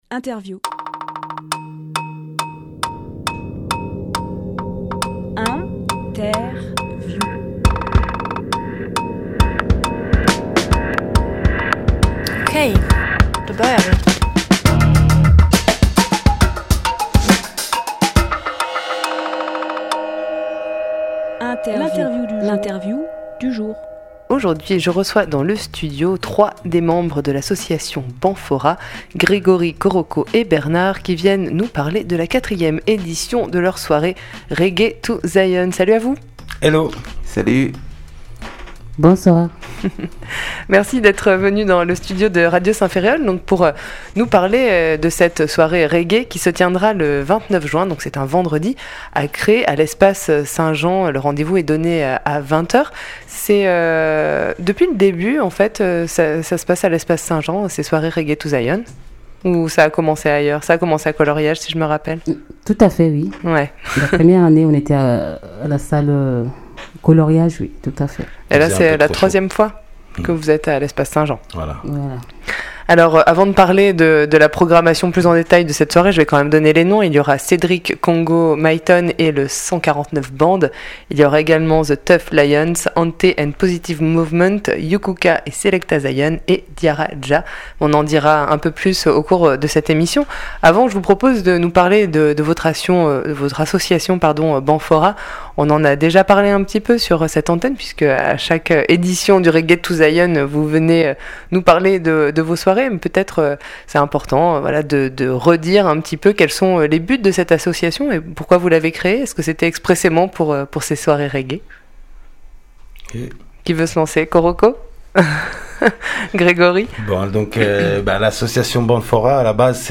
Emission - Interview Reggae to Zion Publié le 19 juin 2018 Partager sur…